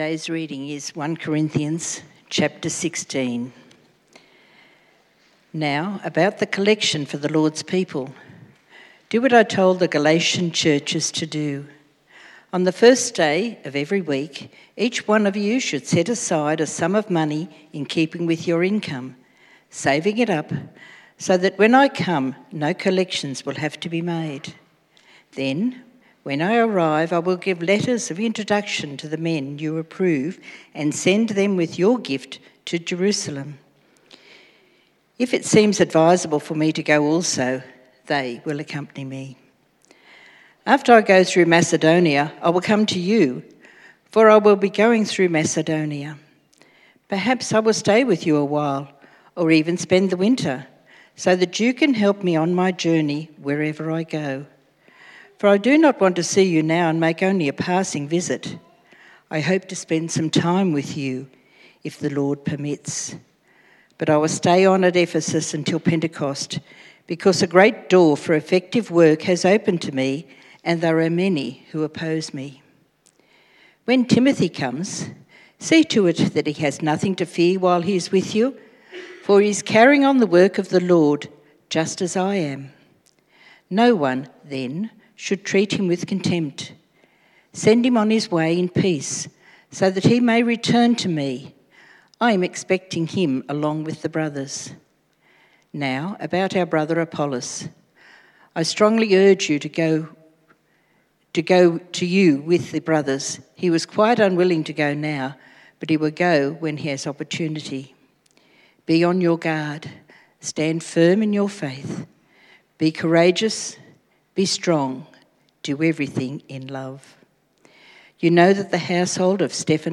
Preacher
Service Type: AM